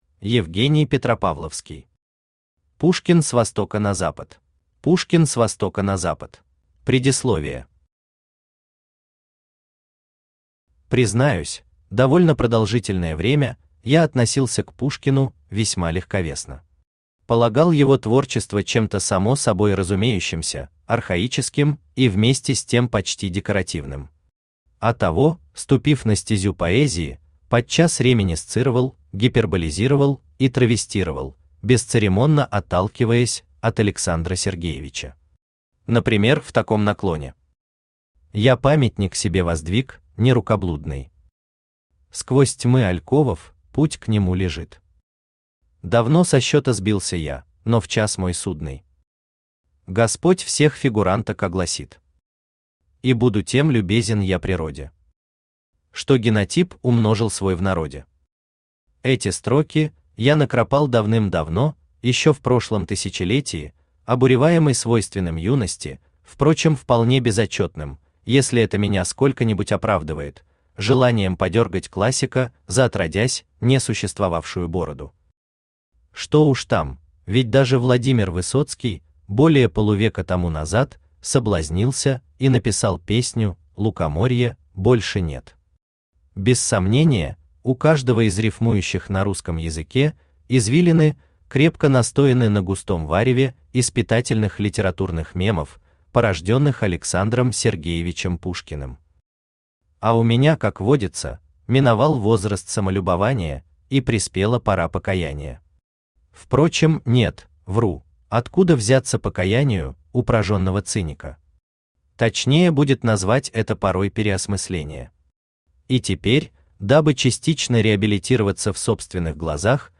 Аудиокнига Пушкин с востока на запад | Библиотека аудиокниг
Aудиокнига Пушкин с востока на запад Автор Евгений Петропавловский Читает аудиокнигу Авточтец ЛитРес.